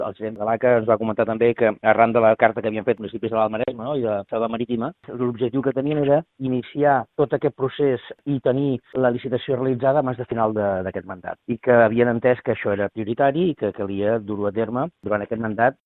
Així ho ha assegurat el president de l’organisme supramunicipal, Francesc Alemany, en declaracions a Ràdio Calella TV.